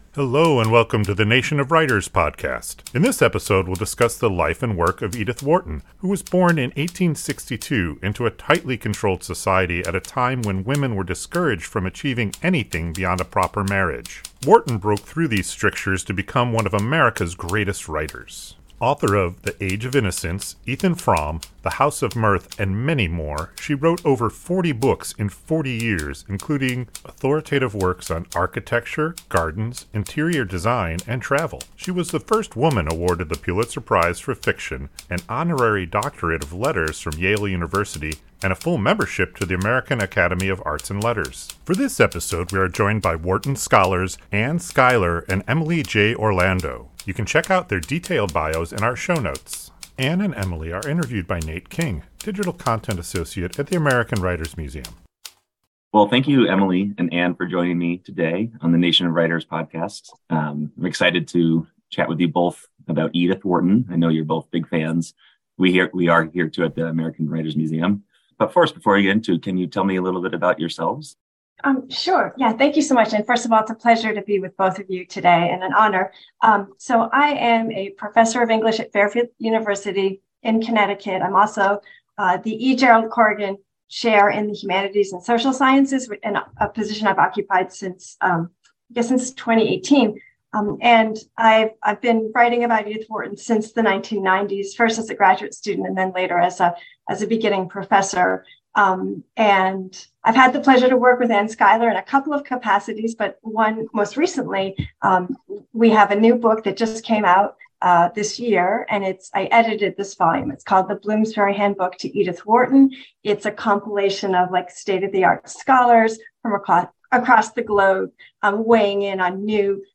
We discuss the legacy of Edith Wharton, the first woman to win a Pulitzer Prize for Fiction, with scholars